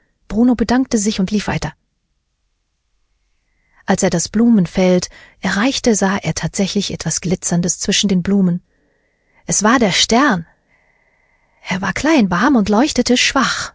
Here a unseen sample with a children's book and a speaker from unseen data with more emotions:
The GRPO model handles unseen speakers and emotional content a bit better, with fewer repetition loops.